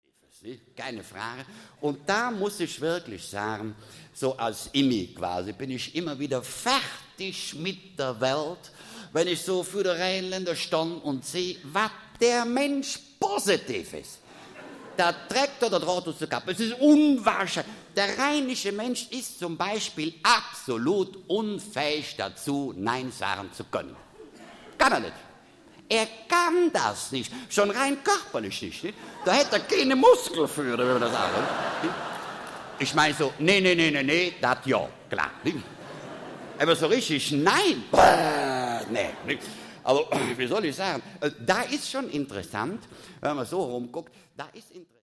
Konrad Beikircher (Sprecher)
Schlagworte AUDIO/Belletristik/Comic, Cartoon, Humor, Satire/Humor, Satire, Kabarett • Audio-CD • Grammatik • Hörbücher • Hörbücher; Kabarett (Audio-CDs) • Hörbuch; Kabarett • Hörbuch; Kabarett (Audio-CDs) • Kabarett • Kabarett (Audio-CDs) • Klangwolken • Köln • Rheinland • Sprachwitz • Westfalen